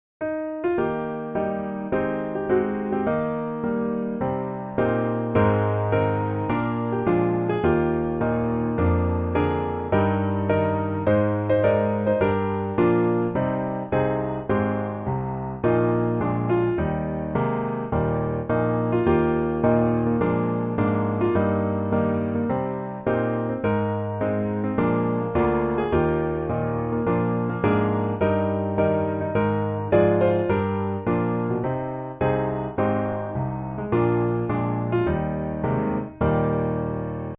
降E大调